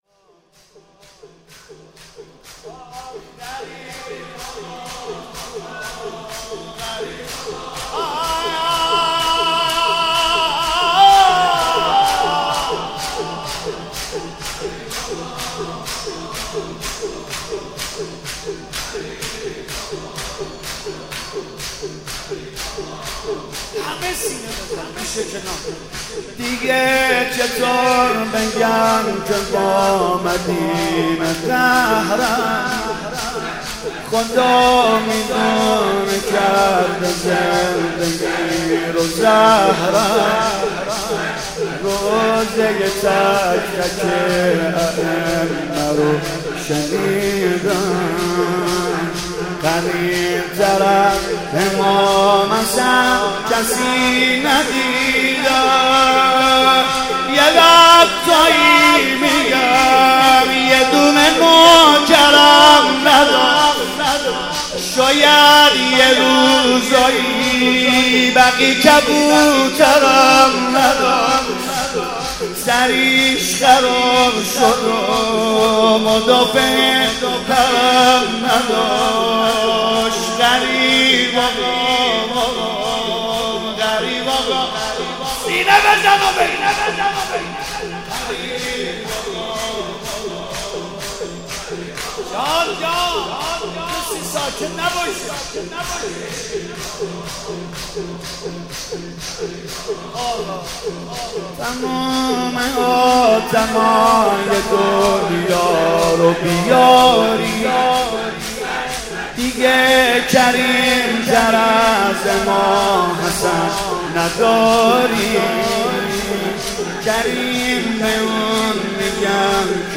(ویژه مناسبت تخریب بقیع) شور: دیگه چطور بگم که با مدینه قهرم